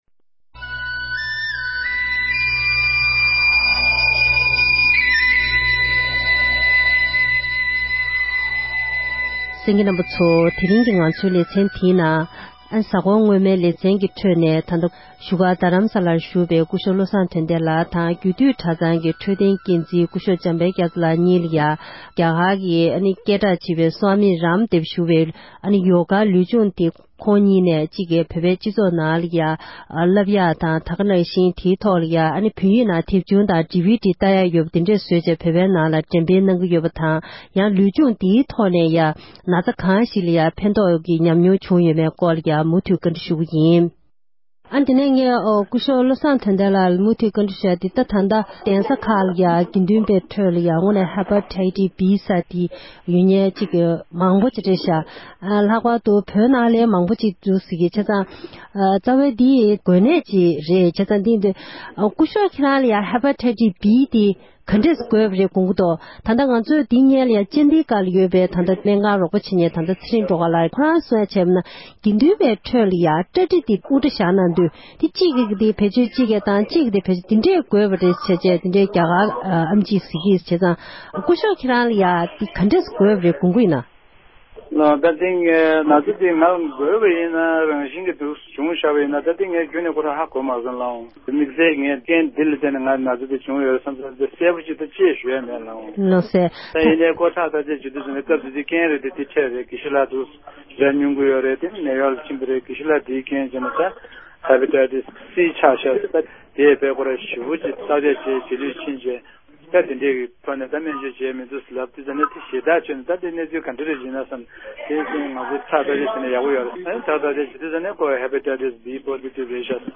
འབྲེལ་ཡོད་མི་སྣ་གཉིས་ལ་བཀའ་འདྲི་ཞུས་པ